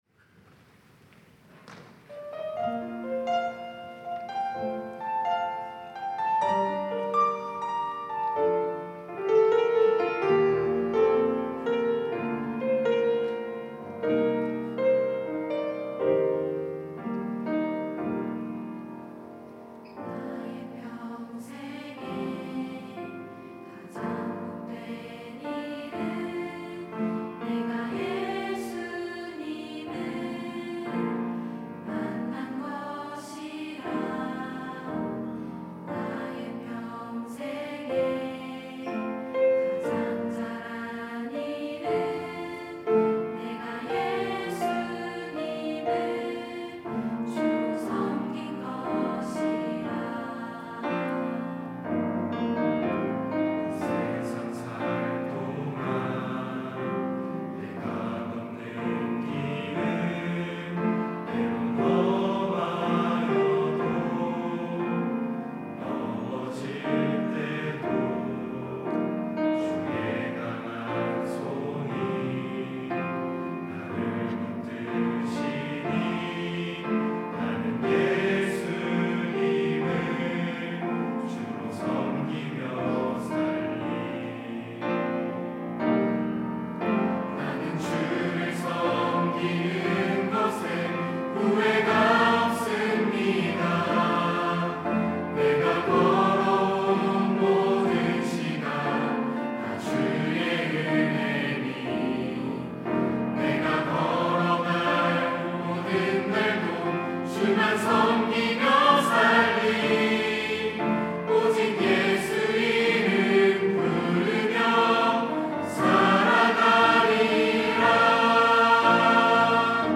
특송과 특주 - 나는 주를 섬기는 것에 후회가 없습니다
청년부 2024 리더쉽